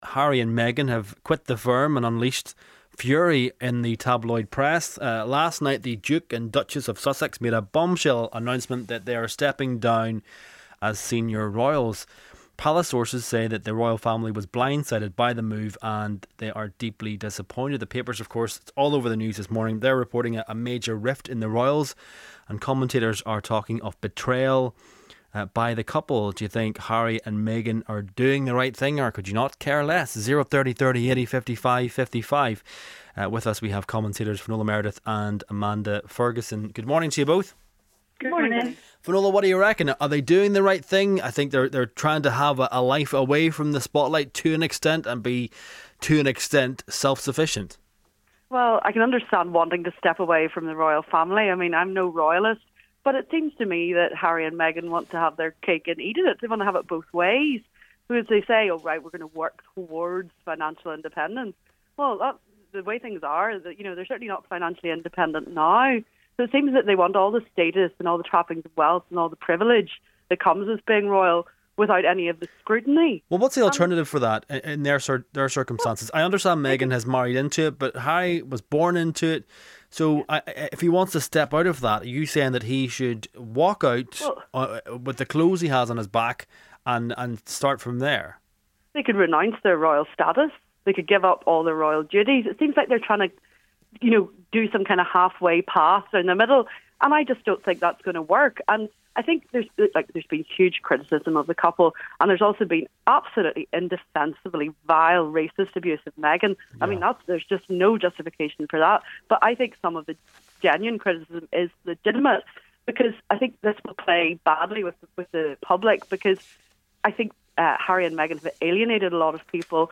talked to the Royal commentator Jenny Bond, a former BBC Royal Correspondent